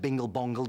Bingle Bongle Dingle Dangle - Notification.wav